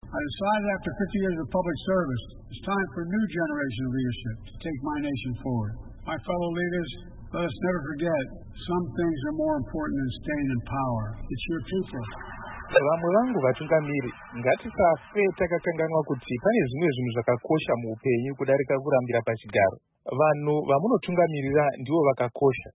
Vakataura mashoko aya pamberi pegungano reUnited Nations General Assembly kanova kekupedzisira kutaura pamberi pegungano iri sezvo vave kuenda pamudyandigere mushure mekutungamira kwemakore mana chete.